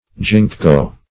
Ginkgo \Gink"go\, n.; pl. Ginkgoes. [Chin., silver fruit.]